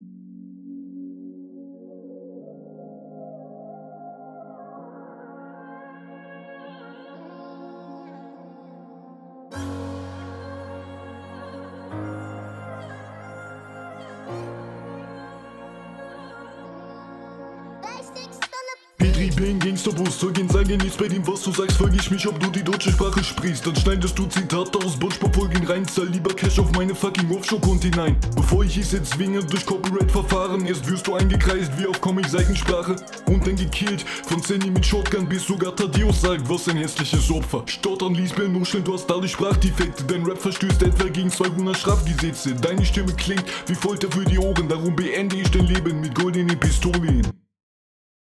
Du bist flowlich hier etwas dünner unterwegs.